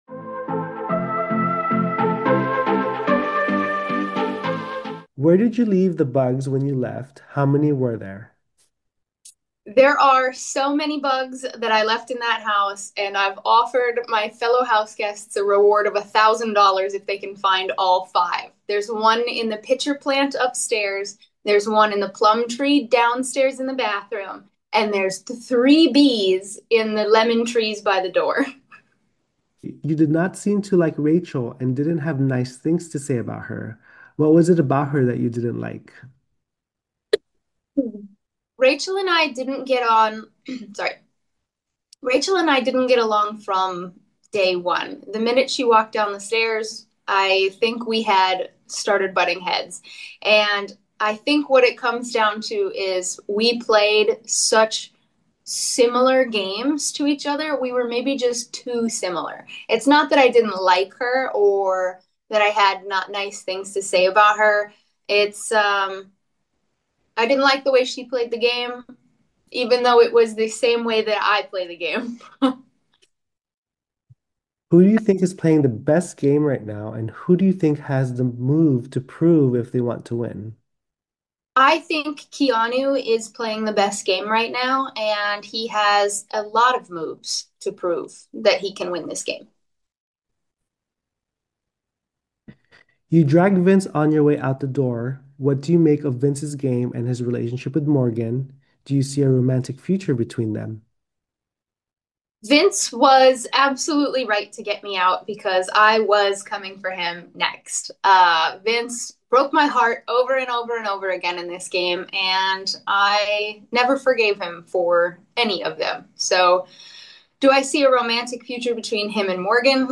Big Brother 27 Exit Interview